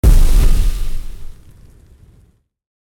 Spell Impact
spell-impact-6.ogg